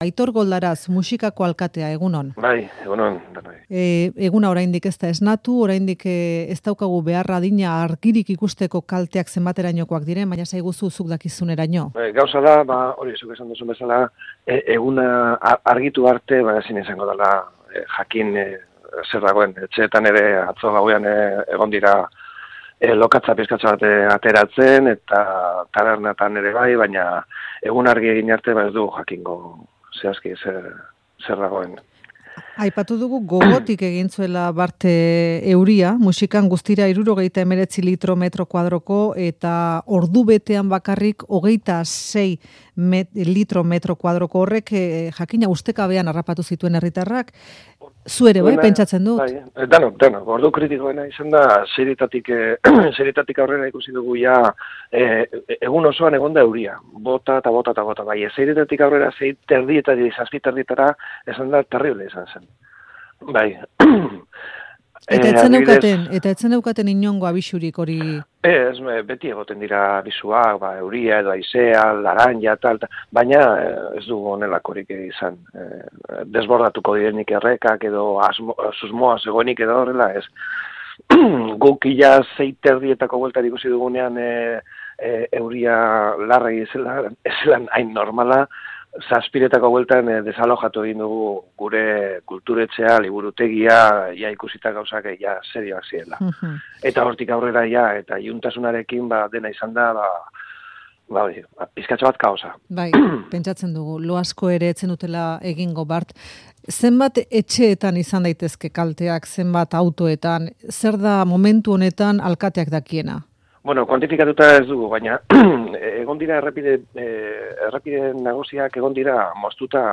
Euskadi Irratia FAKTORIA